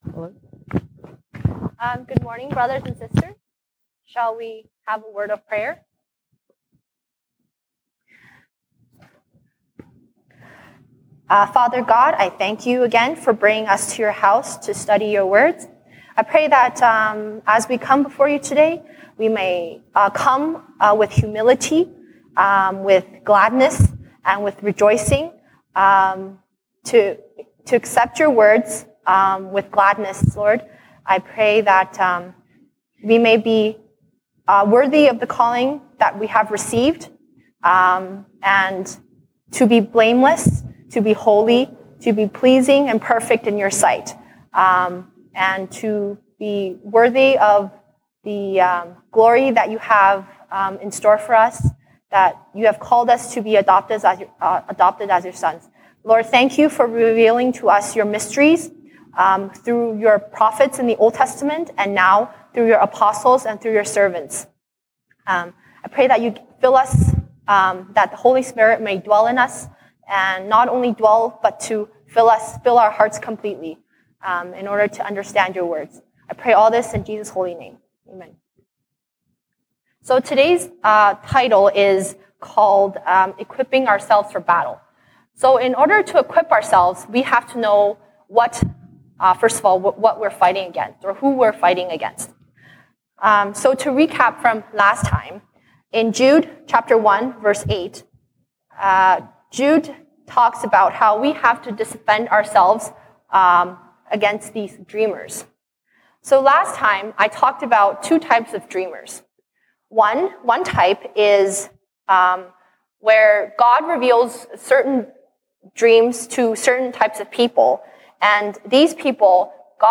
西堂證道 (英語) Sunday Service English: Equipping Ourselves for Battle